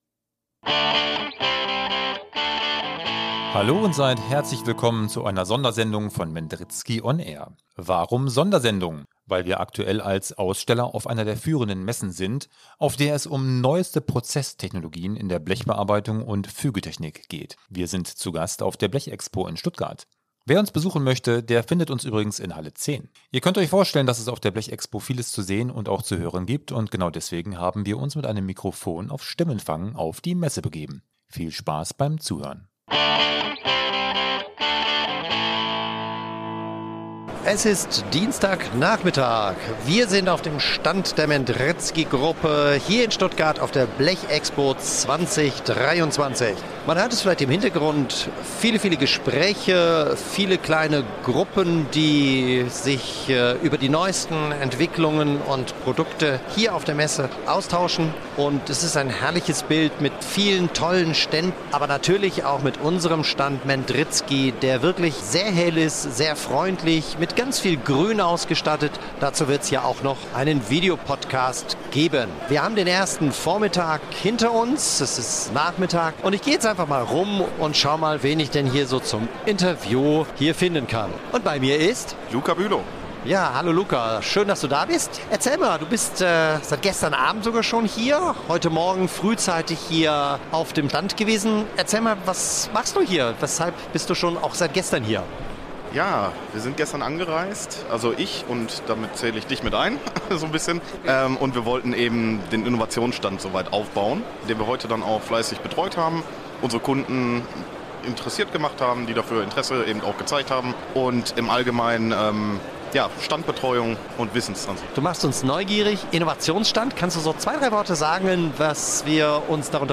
## Sondersendung ## Aktuelles direkt von unserem Messestand auf der Blechexpo 2023